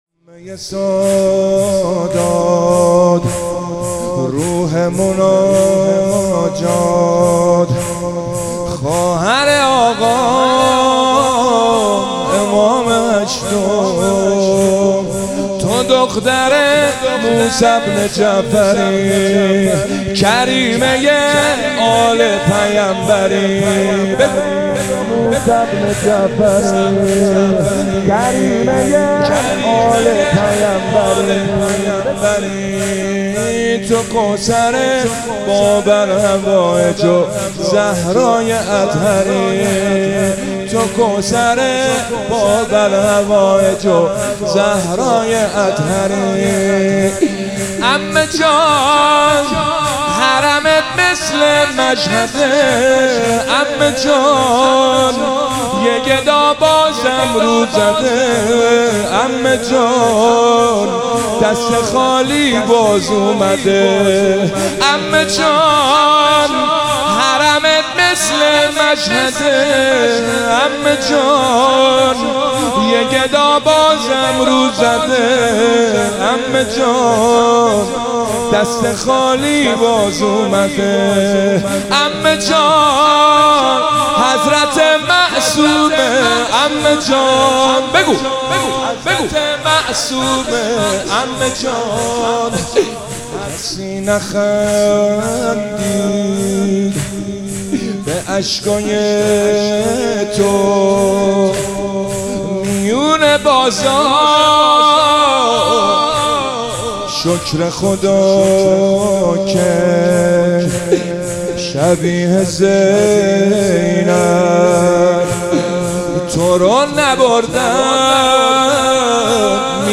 مداحی حاج حسین سیب سرخی | شهادت حضرت معصومه سلام الله علیها 1401 | هیئت غریب مدینه امیرکلا | پلان3